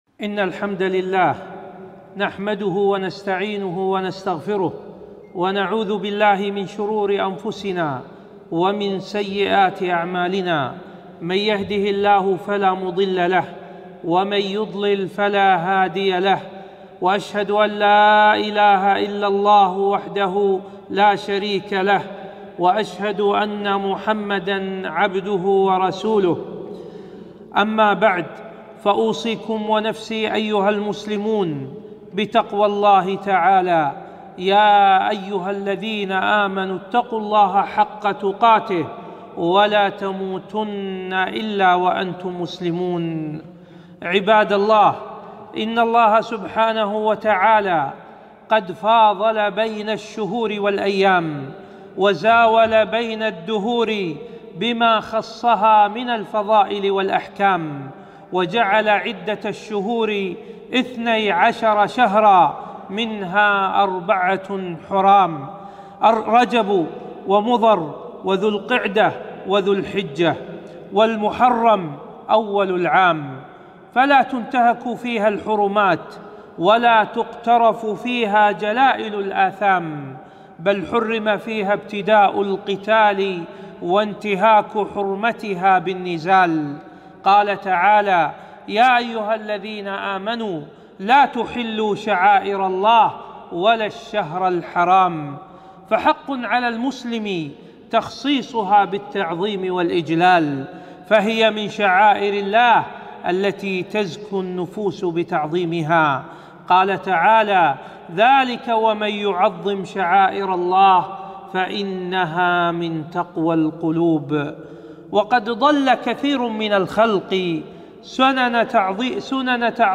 خطبة - سبائك الذهب في التحذير من بدع شهر رجب